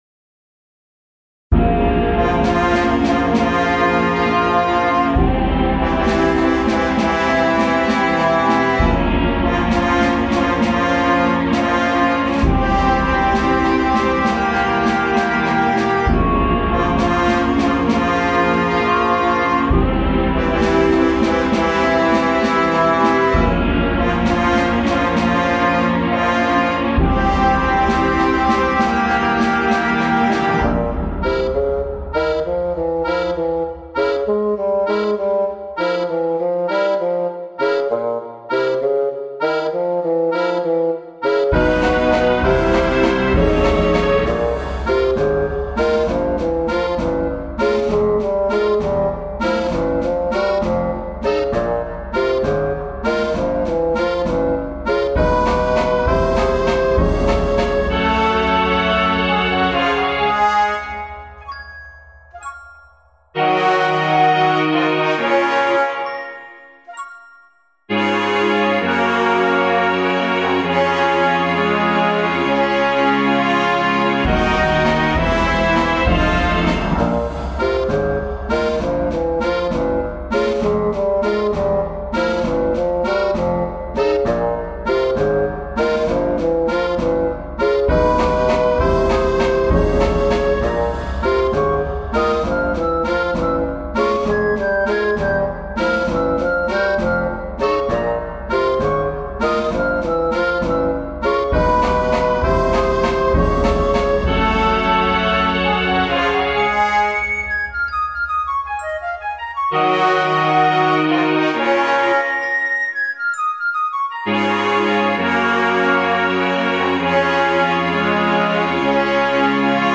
Comically threatening music for taking over the world.